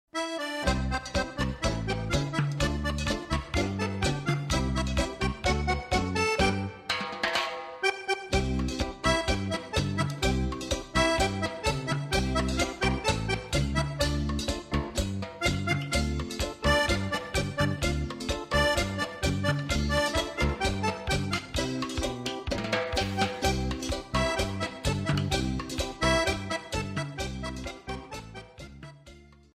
Ballabili Sudamericani
Cha cha cha